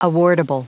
Prononciation du mot awardable en anglais (fichier audio)
Prononciation du mot : awardable